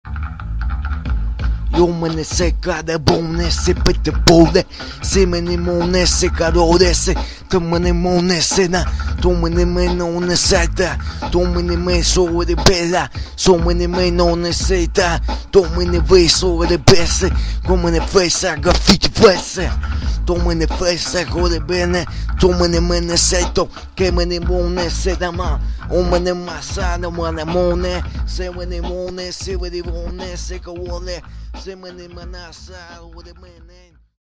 Подача мощная, аж поп-фильтр не выдерживает звуков "Пэ"
придуманный язык какой то ахахахха